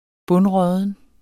Udtale [ ˈbɔnˈʁʌðən ]